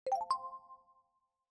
transition-1.mp3